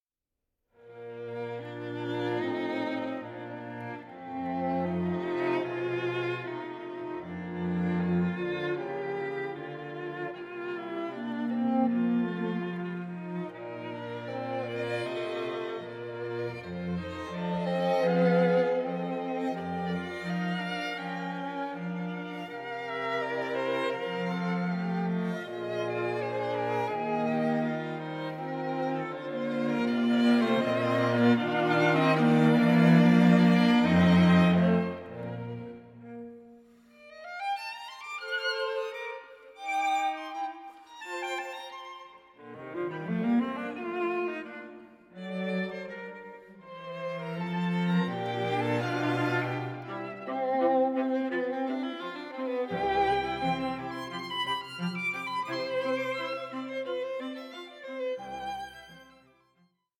Andante con moto 7:33